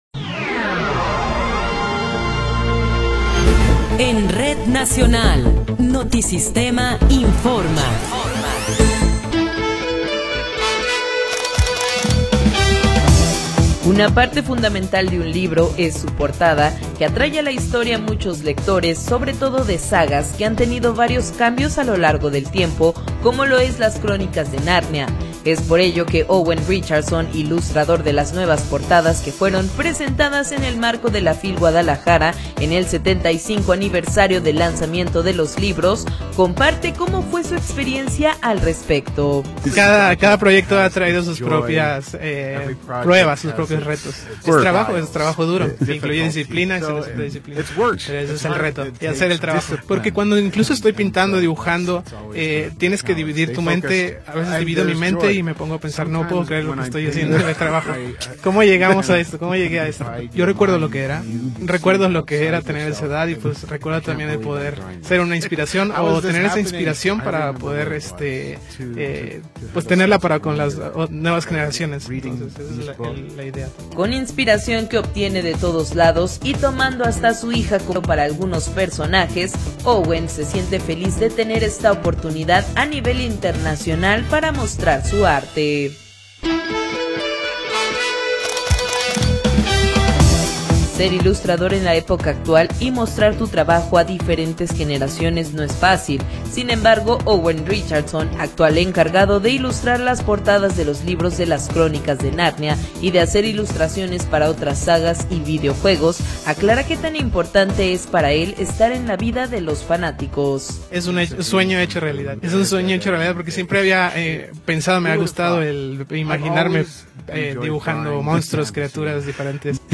Noticiero 19 hrs. – 1 de Febrero de 2026